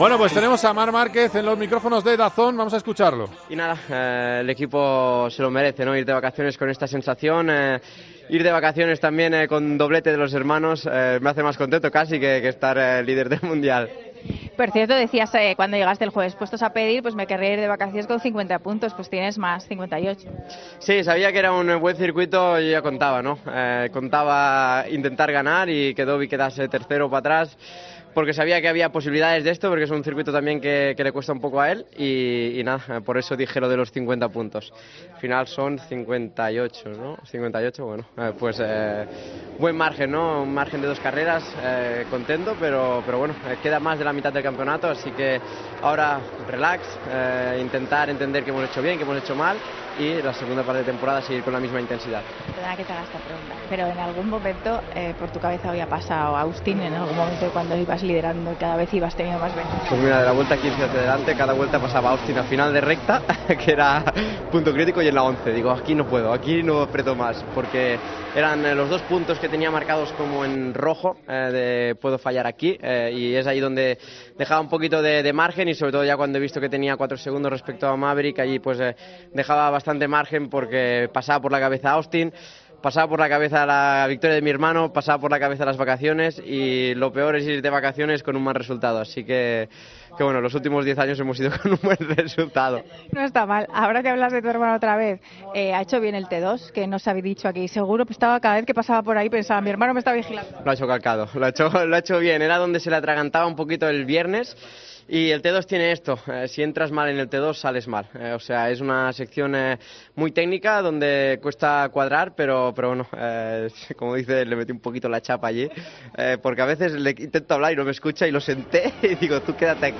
Escuchamos al piloto español en los micrófonos de Dazn tras conseguir la victoria en Alemania.